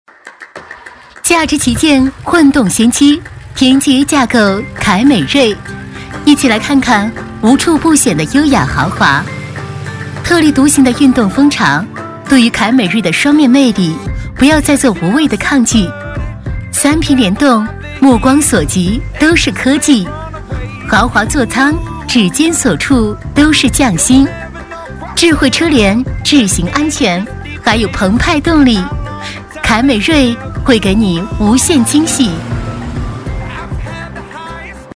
女108-产品广告《丰田》-轻松活泼.mp3